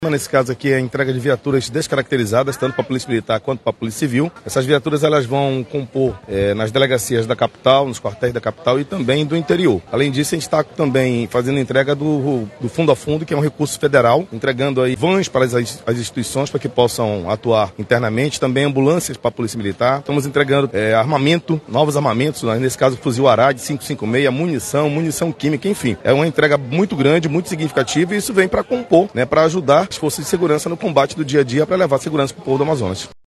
Foram entregues viaturas descaracterizadas para o sistema de inteligência, que vão ajudar no combate ao crime organizado, como explica o secretário de segurança pública, Coronel Vinícius Almeida.
Sonora-Vinicius-Almeidda-.mp3